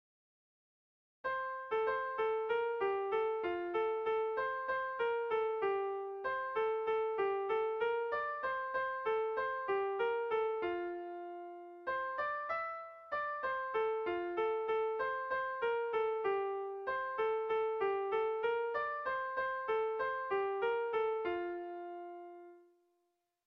Melodías de bertsos - Ver ficha   Más información sobre esta sección
Irrizkoa
ABDB